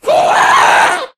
mob / ghast / scream4.ogg
scream4.ogg